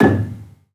Gamelan
Gambang-G#1-f.wav